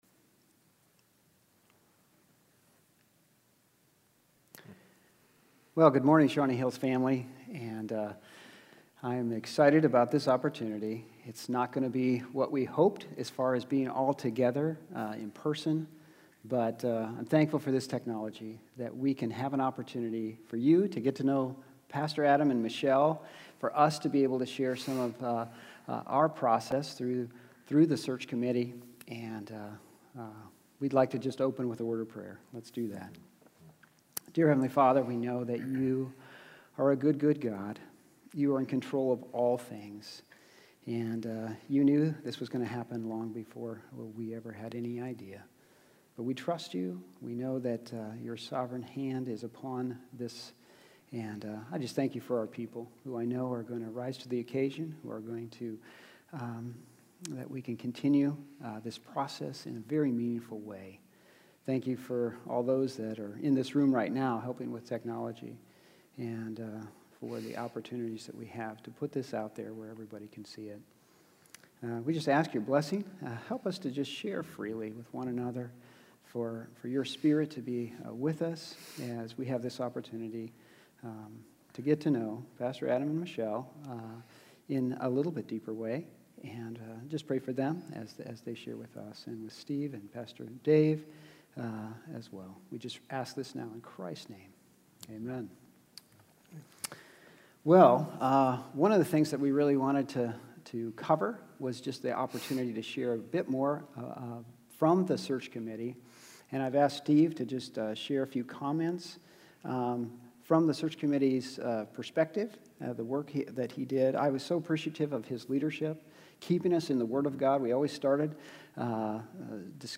Question and Answer Session